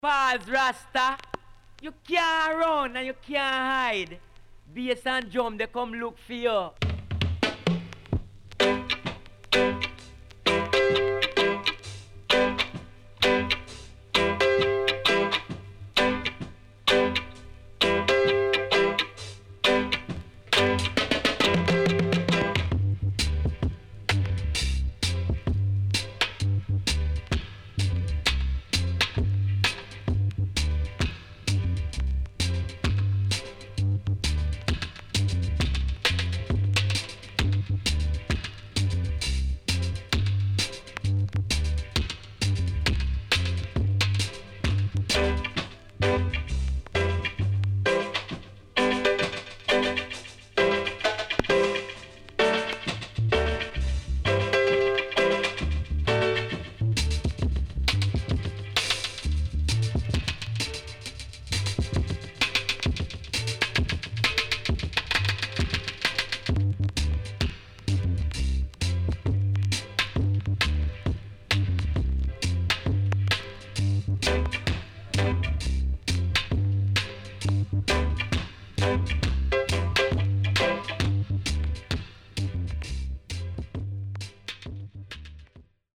HOME > REGGAE / ROOTS
SIDE A:所々チリノイズがあり、少しプチパチノイズ入ります。